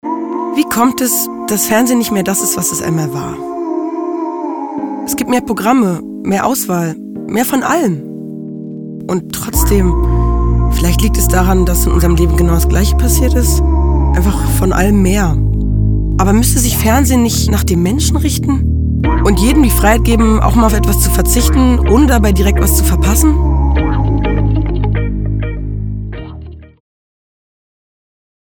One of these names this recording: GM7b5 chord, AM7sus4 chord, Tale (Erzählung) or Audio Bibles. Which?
Tale (Erzählung)